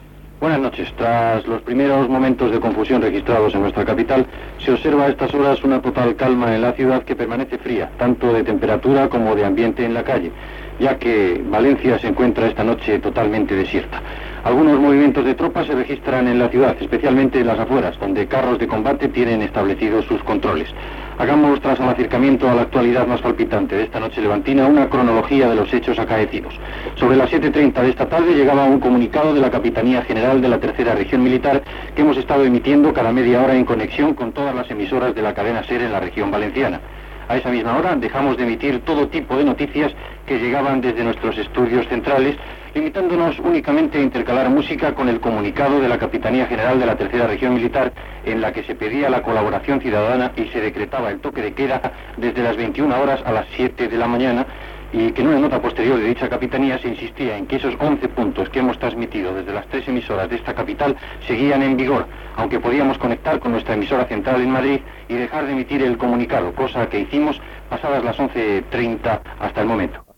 Informació des de València, la nit del dia del cop d'estat del 23 de febrer
Informatiu